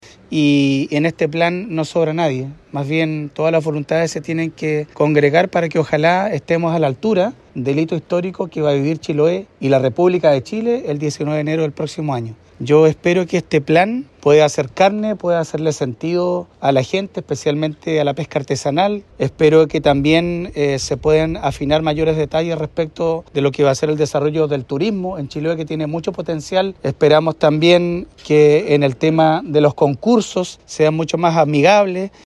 alcaldes-min.mp3